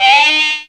OVERBLOW 2.wav